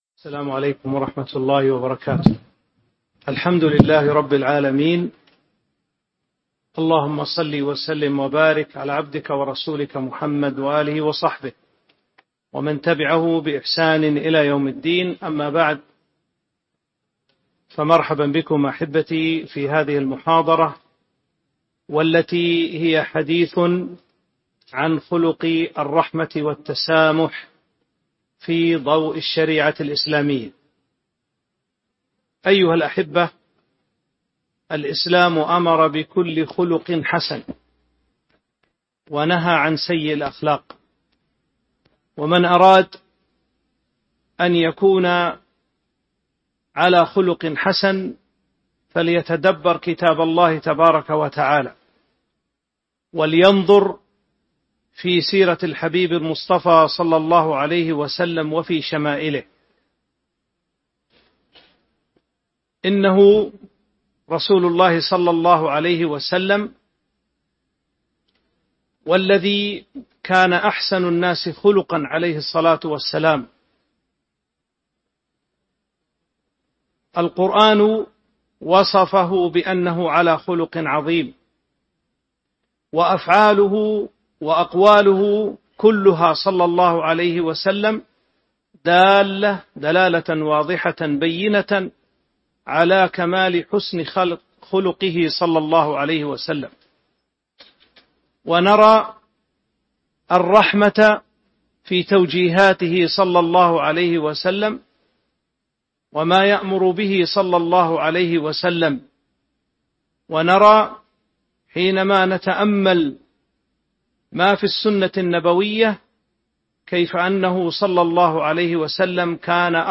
تاريخ النشر ١٩ جمادى الأولى ١٤٤٦ هـ المكان: المسجد النبوي الشيخ